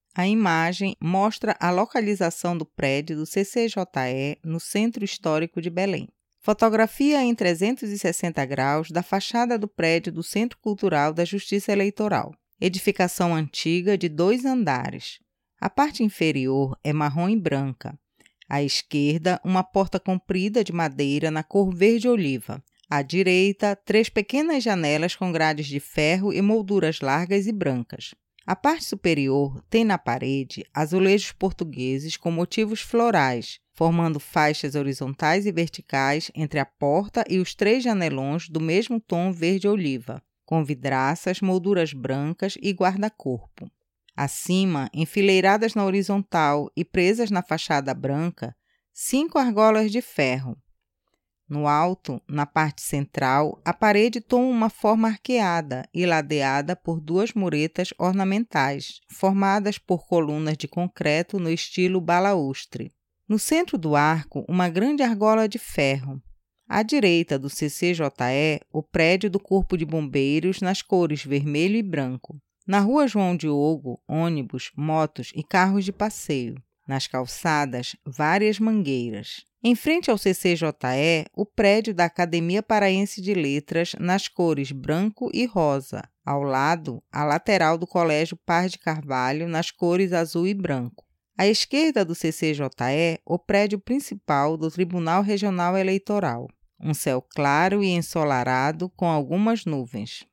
Localização do Prédio CCJE audiodescrição